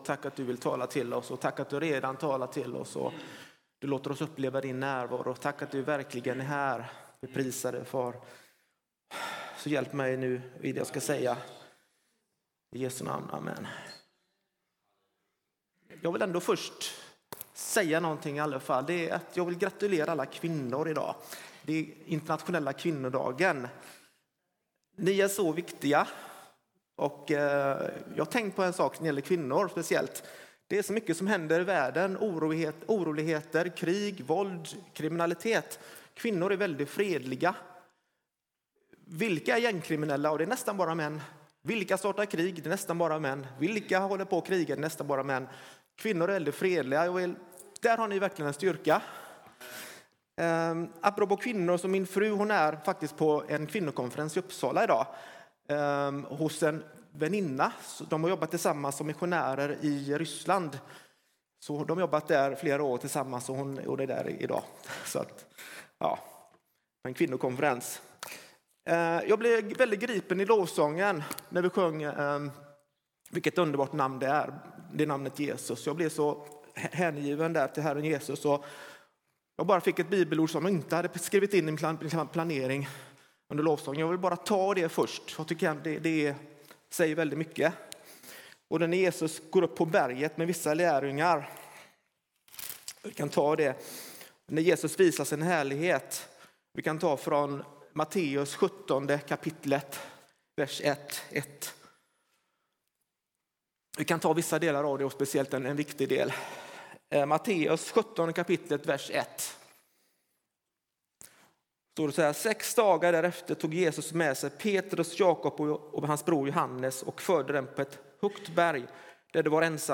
Undervisning och predikningar från Sveriges kyrkor.
Storegårdskyrkan söndag 08 mars 2026